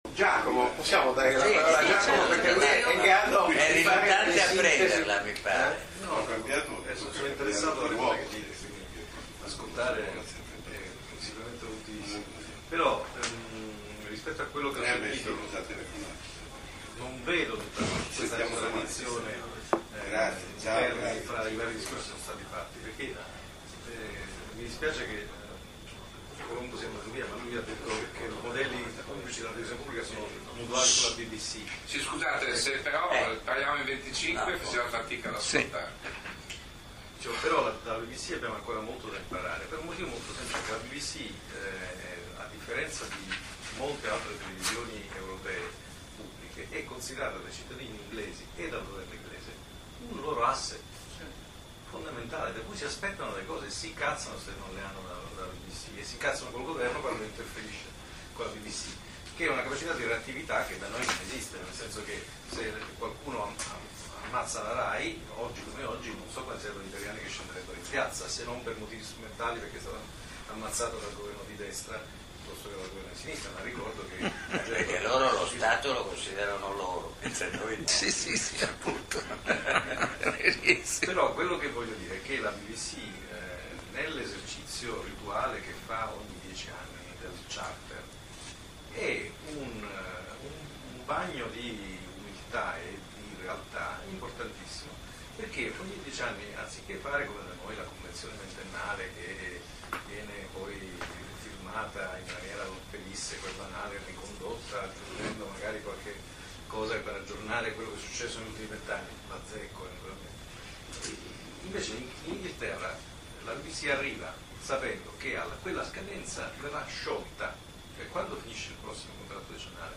Seminario di Infocivica in collaborazione con Globus et Locus Milano - 15 gennaio 2009